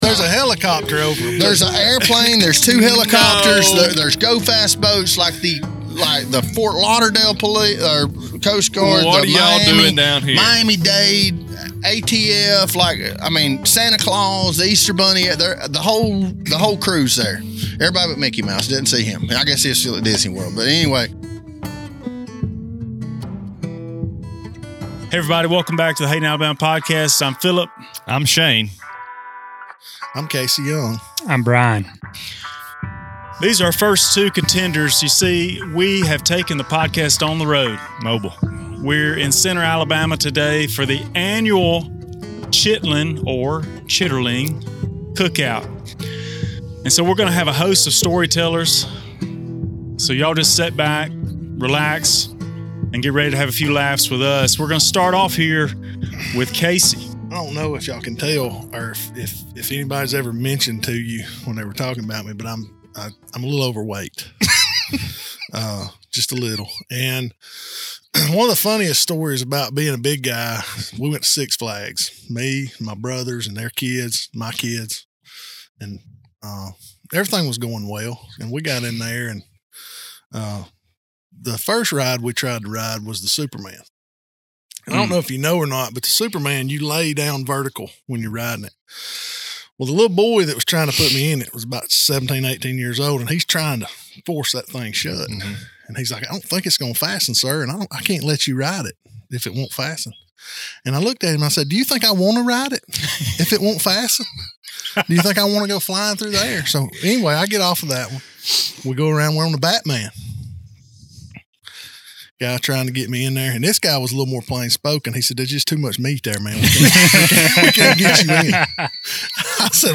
Amidst the festivities, our hosts engage with four locals, each with a unique story to share. From tales of triumph to reflections on heritage, these narratives offer a glimpse into the soul of Centre.